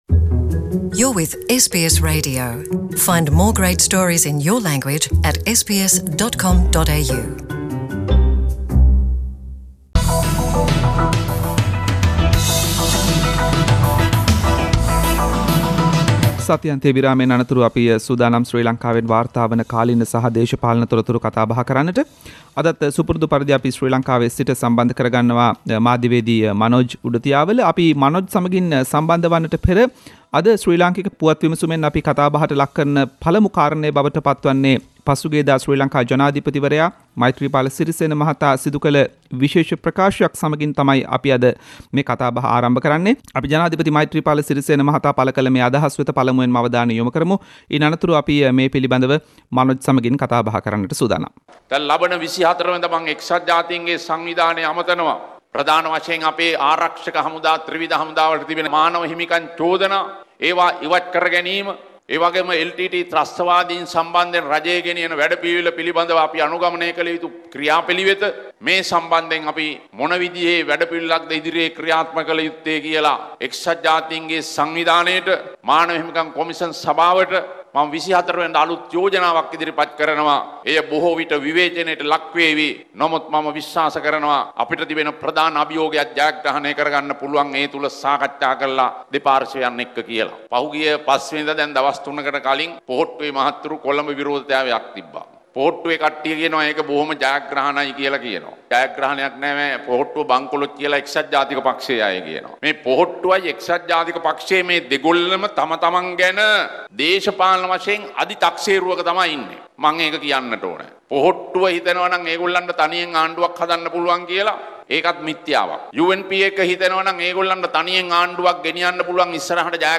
එක්සත් ජාතීන්ගේ සංවිධානය හමුවේ ජනාධිපති මෛත්‍රීපාල සිරිසේන මහතාගෙන් විශේෂ ප්‍රකාශයක් - “SBS සිංහල” සතියේ දේශපාලනික විත්ති විමසුම
ශ්‍රී ලංකාවේ සිට වාර්තා කරයි